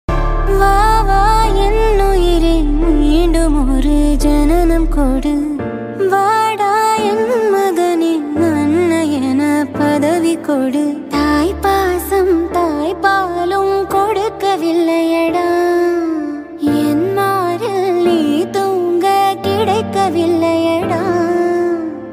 Ringtones, Tamil Ringtones